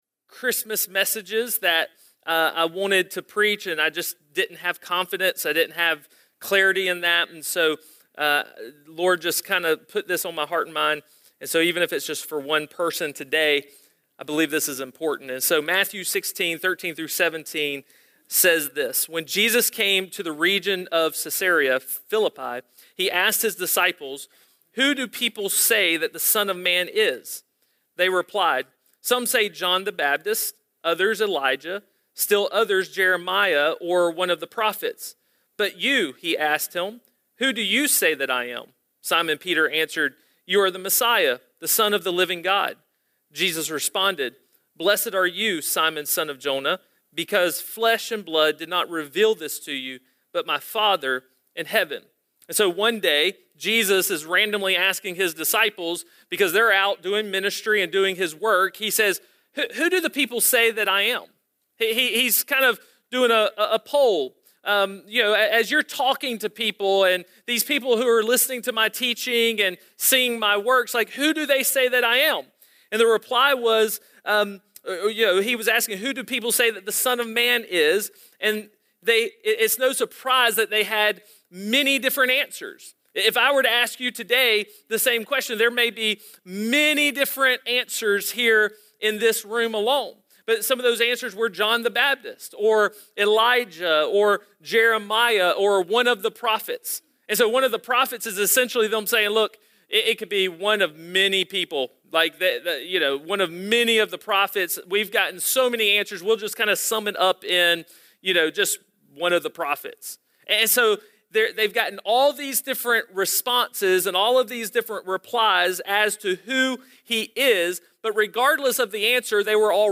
Sermons – Hope Church Augusta